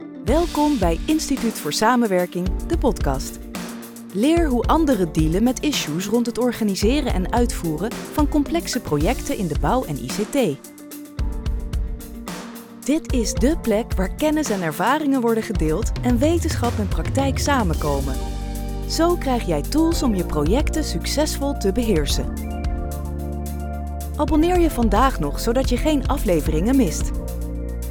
I am a professional Dutch Voice-over with a clear, warm and fresh voice.